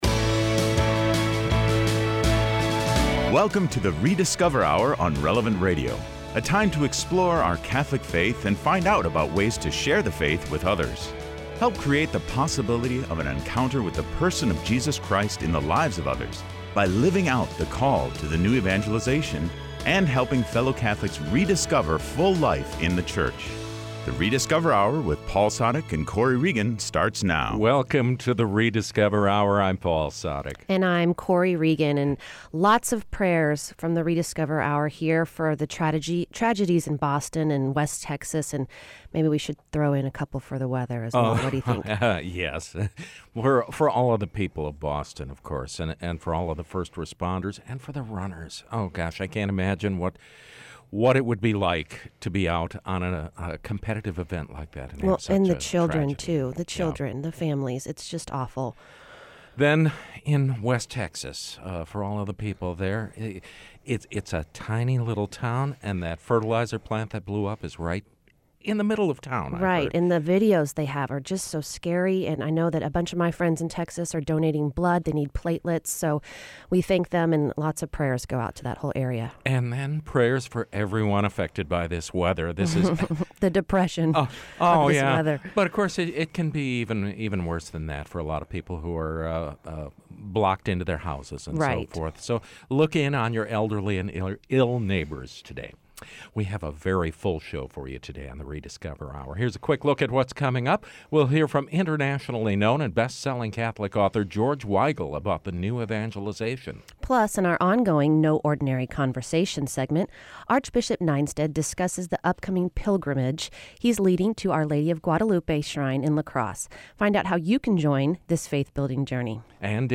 Hemos hablado con George Weigel, autor de éxito y reconocido internacionalmente, quien acaba de visitar nuestra ciudad y se tomó unos minutos para conversar con nosotros sobre la Nueva Evangelización.
Y en nuestro segmento «Invitation Situation», escucharemos una conversación entre amigos sobre la exploración de la fe... y el fortalecimiento de su amistad en el proceso.